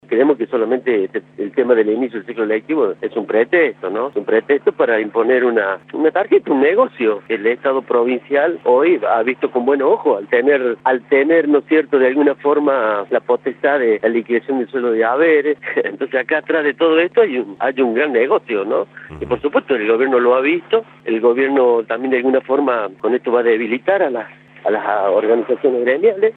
Nito Brizuela, titular de Ambiente, por Radio La Red